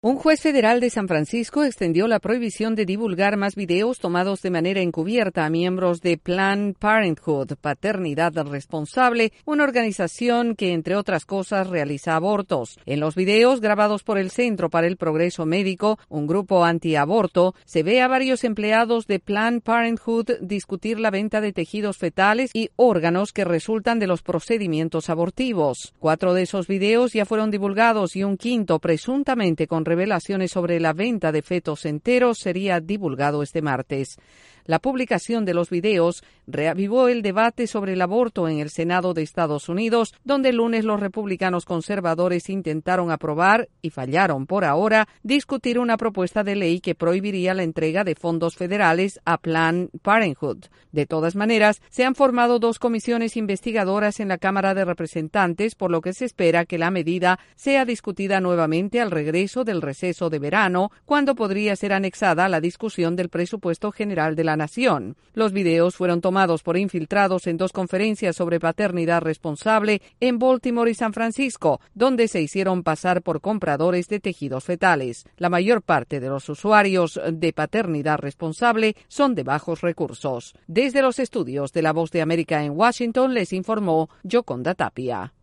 La justicia prohíbe la difusión de videos de la organización Paternidad Responsable.. Desde la Voz de América en Washington informe